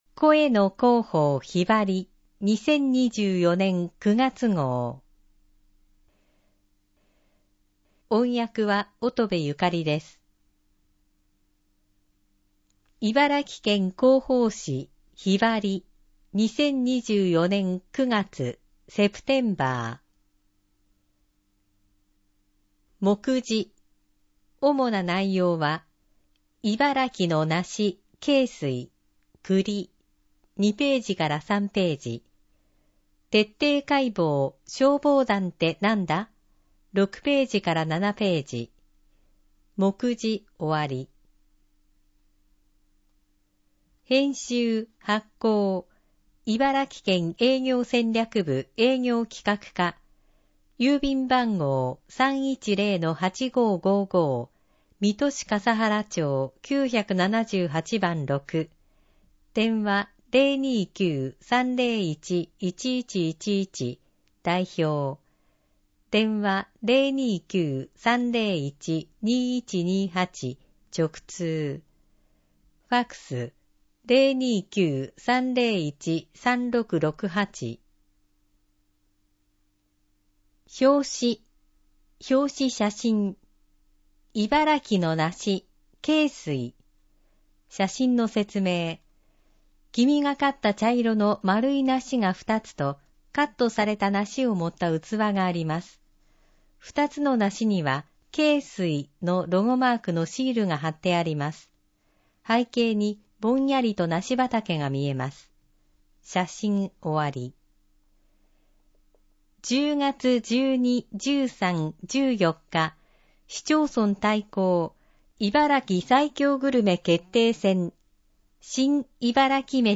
音声版・点字版 視覚障害の方を対象に音声版 も発行しています。